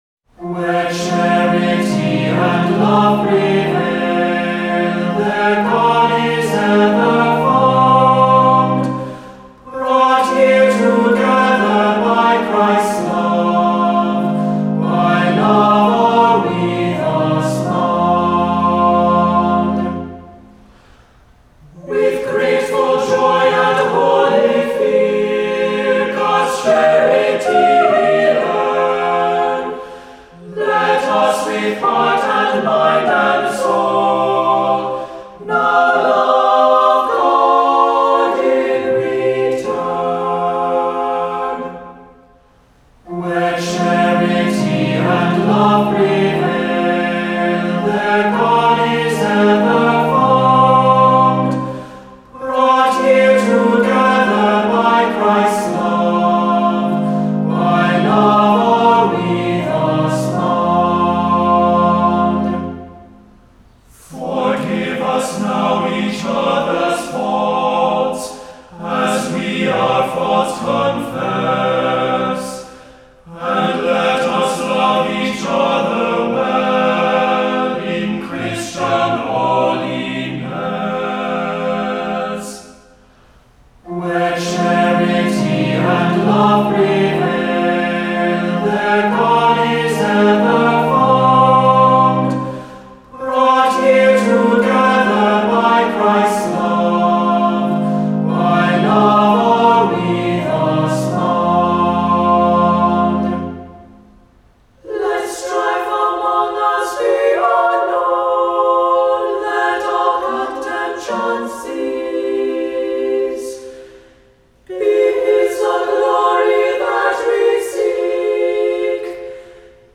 Voicing: SSAATTB